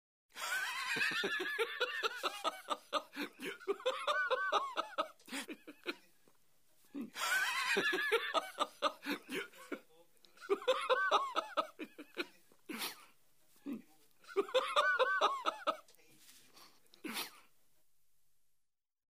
Звуки мужского смеха
Реакция на шутку